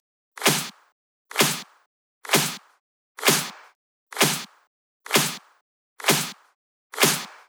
VDE2 128BPM Wasabi Drums 4.wav